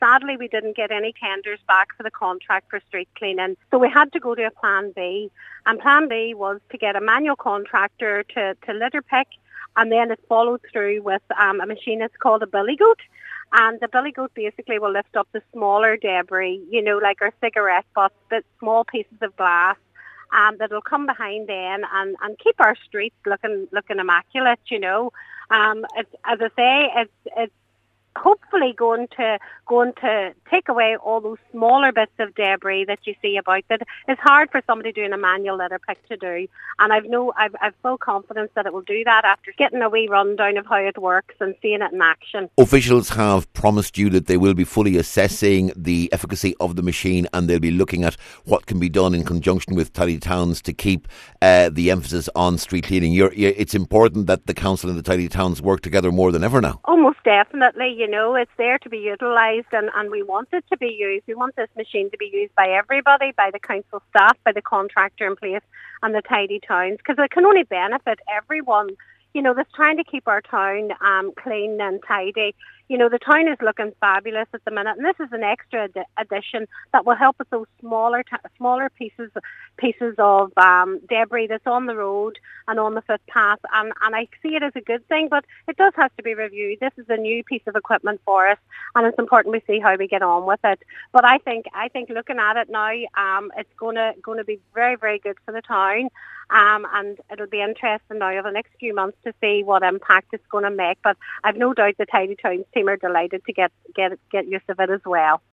This week, officials told Cllr Joy Beard that the efficacy of the ,machine will be assessed on an ongoing basis, and after a demonstration yesterday which was also attended by Councillors Fionan Bradley and Jack Murray, Cllr Beard says she’s in no doubt this have a very significant impact……….